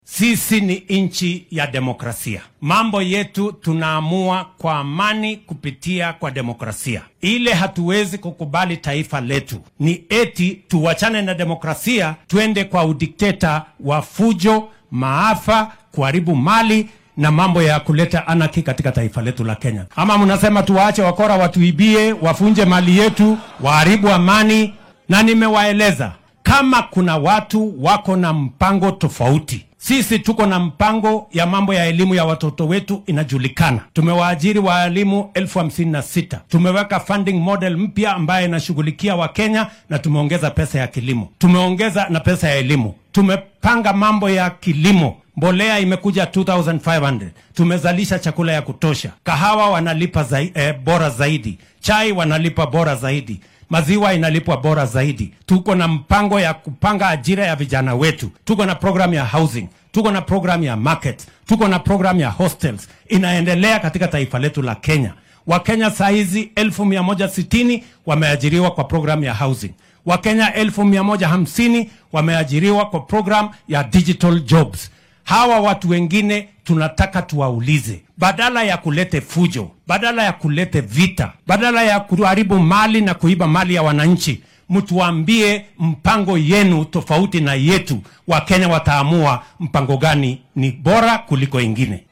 Madaxweynaha dalka William Ruto ayaa xilli uu maanta ku sugnaa degmada Chebango ee ismaamulka Bomet sheegay in uunan marnaba oggolaan doonin in rabshado la adeegsado si kasta oo loo doonaya isbeddel hoggaamineed.